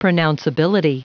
Prononciation du mot pronounceability en anglais (fichier audio)
Prononciation du mot : pronounceability
pronounceability.wav